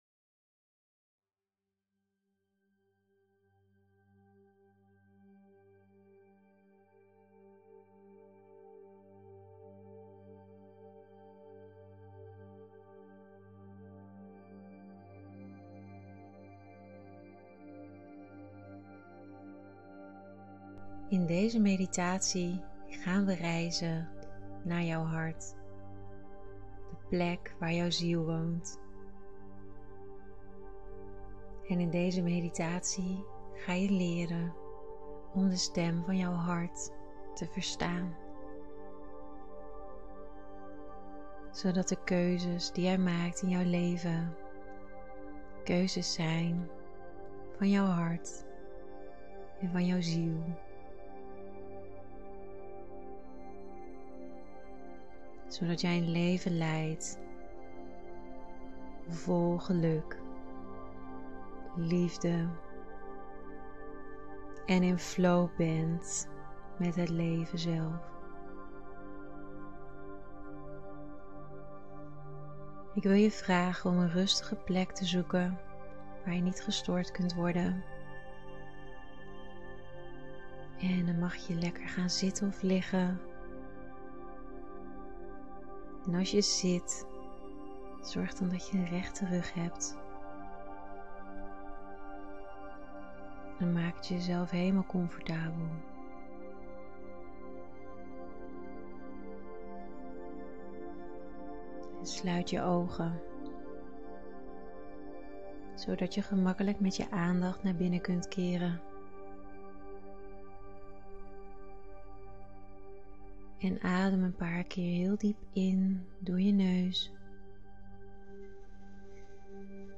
De+stem+van+jouw+hart+-+meditatie!-1f67ada7.m4a